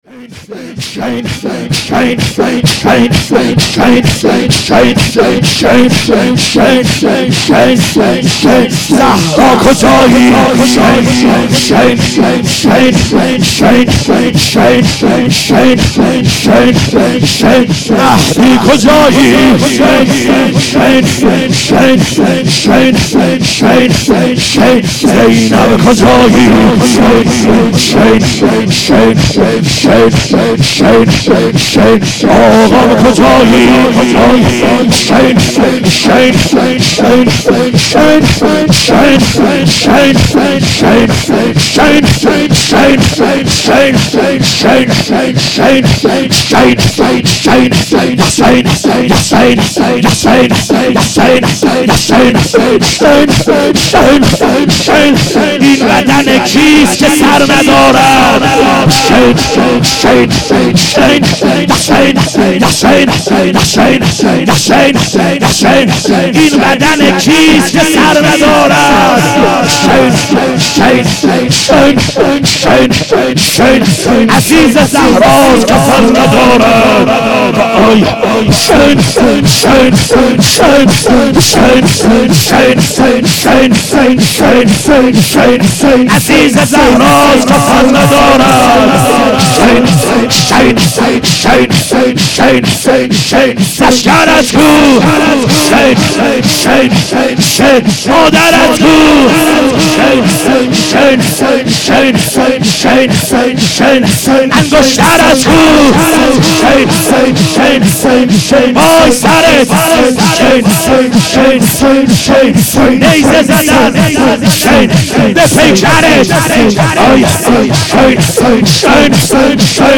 لطمه زنی
دهه آخر صفر | شب اول